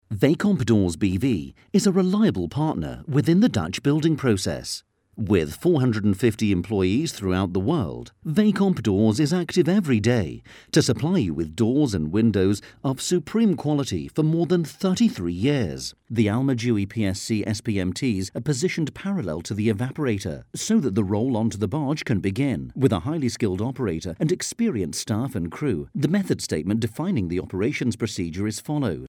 Friendly, natural yet corporate sounding neutral British English voice.
Sprechprobe: Industrie (Muttersprache):
Friendly, natural corporate neutral UK English.